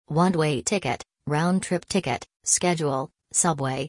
American English (Use This)